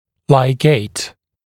[laɪˈgeɪt][лайˈгейт]лигировать, накладывать лигатуру